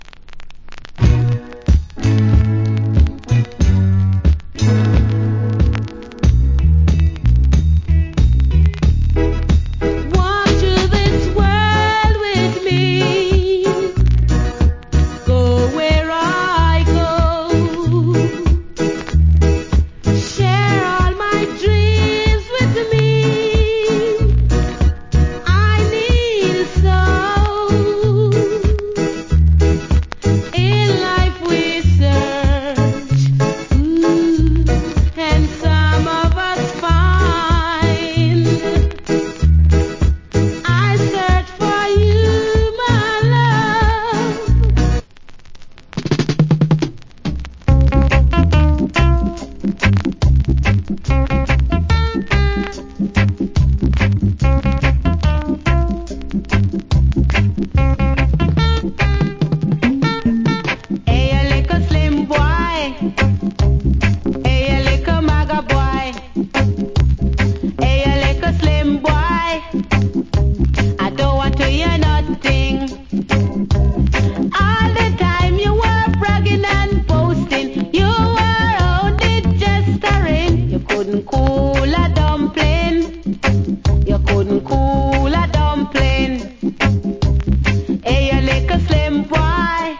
Nice Reggael.